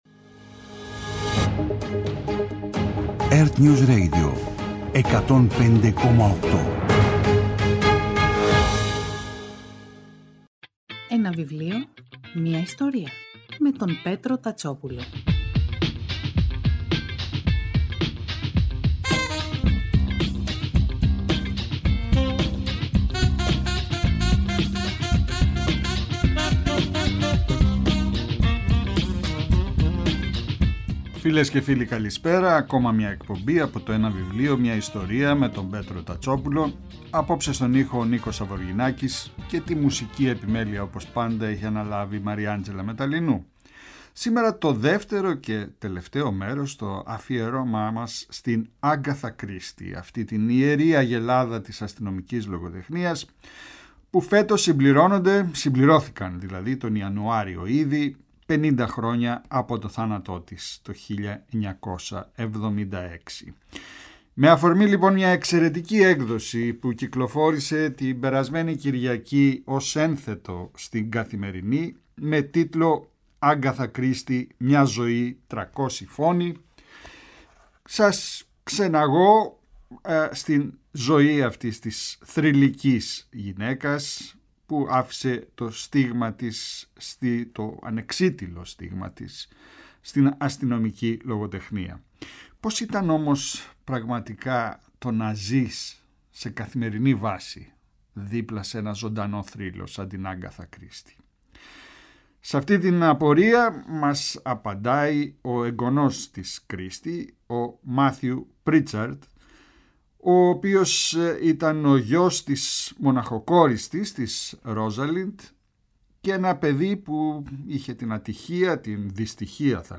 Κάθε Σάββατο και Κυριακή, στις 5 το απόγευμα, ο Πέτρος Τατσόπουλος παρουσιάζει ένα συγγραφικό έργο, με έμφαση στην τρέχουσα εκδοτική παραγωγή, αλλά και παλαιότερες εκδόσεις.